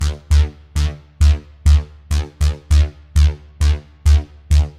Dance music bass loop - 100bpm 55